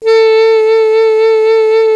55AF-SAX07-A.wav